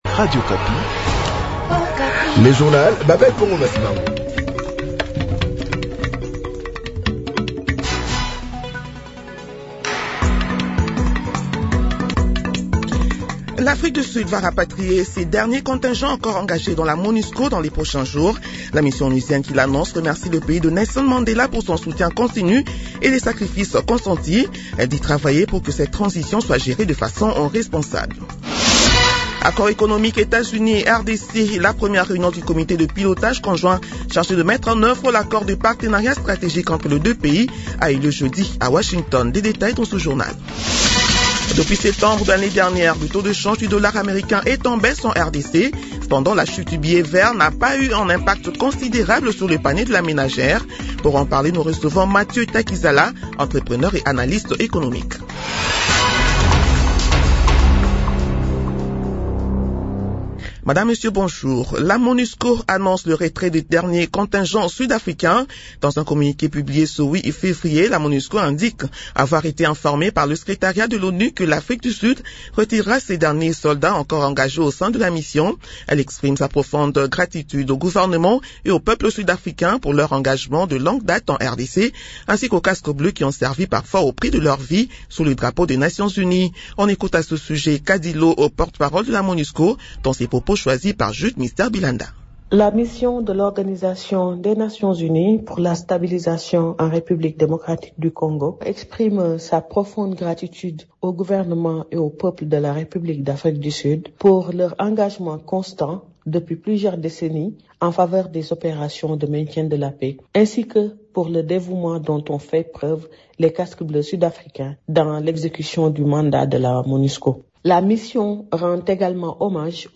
Journal de 6 heures de ce lundi 9 février 2026